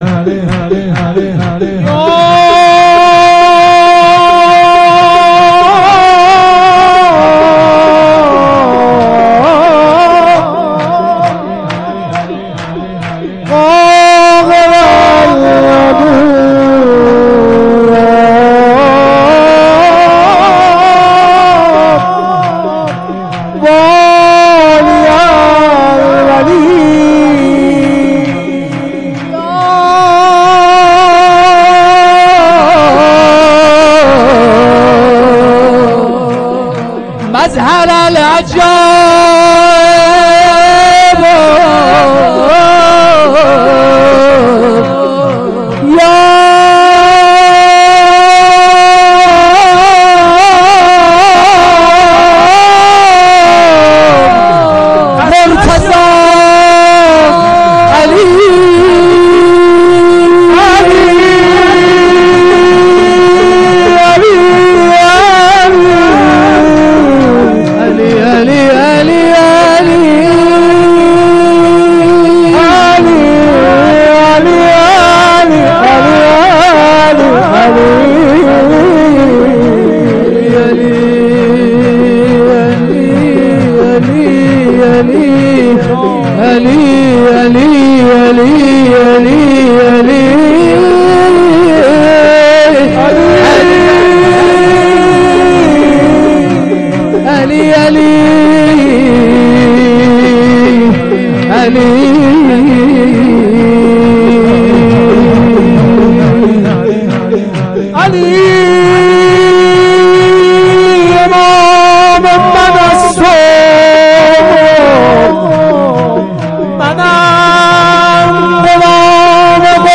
جلسه مذهبی زیارت آل یاسین باغشهر اسلامیه